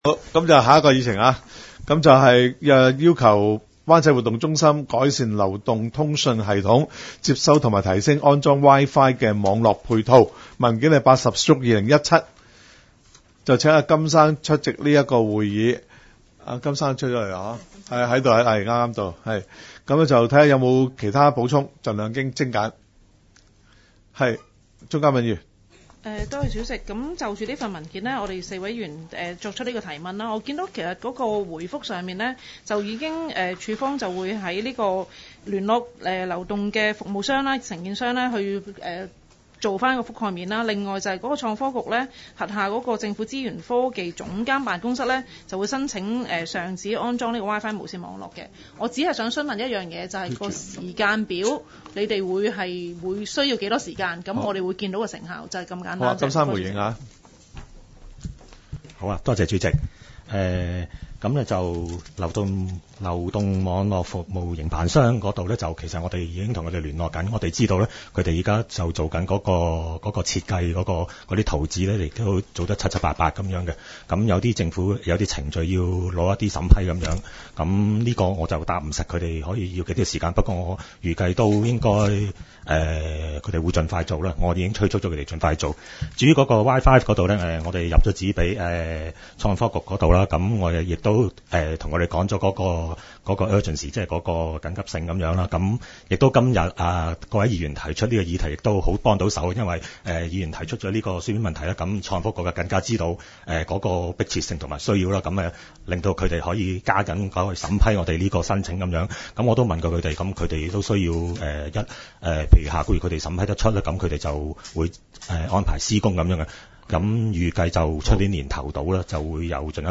区议会大会的录音记录
湾仔区议会第十二次会议